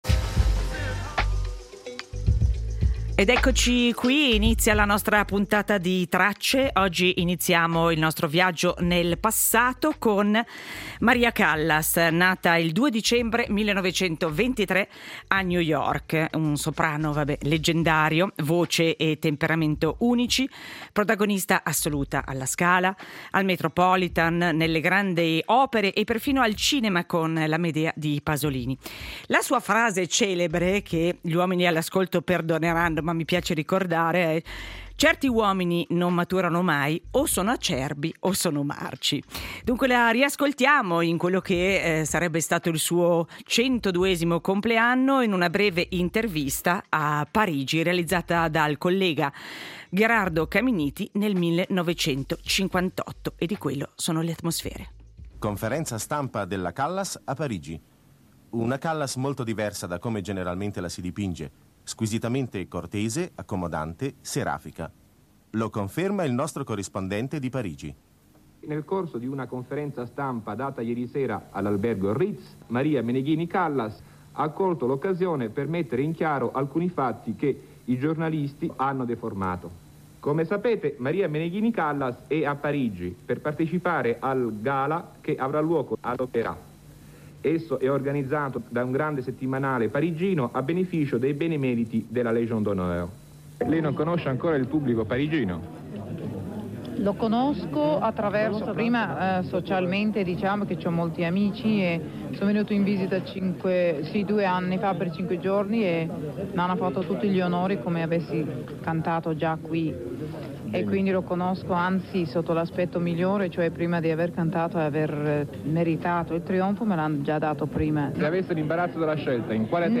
La voce di Maria Callas nel 1958 per ricordarne il compleanno oggi: 2 dicembre del 1923, a Tenero Contra viene dedicata una Fondazione al neurofisiologo premio Nobel che lì aveva trascorso gli ultimi anni della sua vita. Il 2 dicembre di 43 anni fa veniva trapiantato il primo cuore artificiale e veniva assassinato a Miami Gianni Versace.